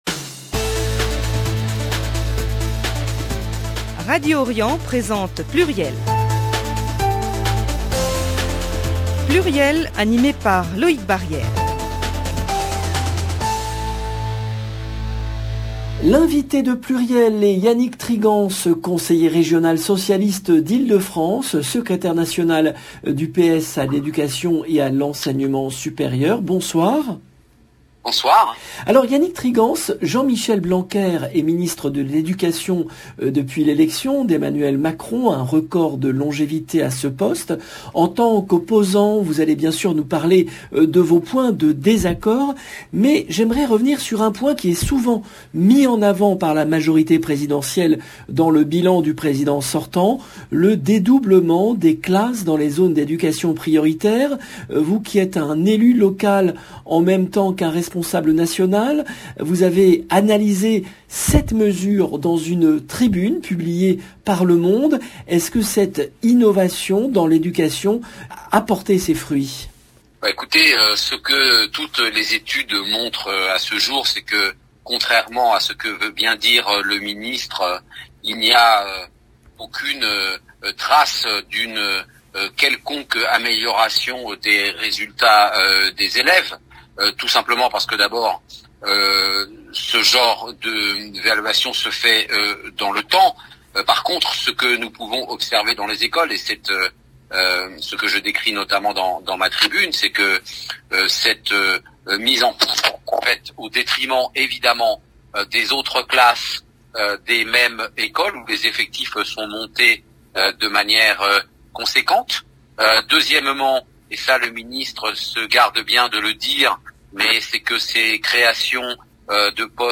L’invité de PLURIEL est Yannick Trigance, conseiller régional socialiste d’Ile de France, secrétaire national du PS à l’Education et à l’Enseignement Supérieur. Il évoque le bilan du gouvernement en matière d'éducation et s'exprime sur les propositons sur l'école d'Anne Hidalgo, candidate PS à l'élection présidentelle.